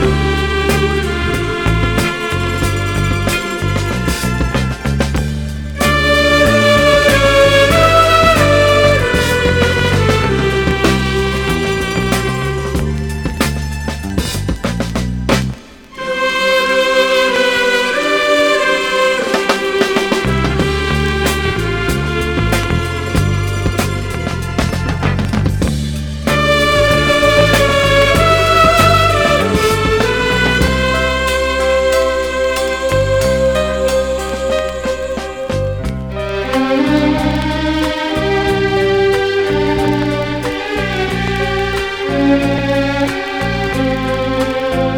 ROCK / 70'S / PROGRESSIVE ROCK / DRUM BREAK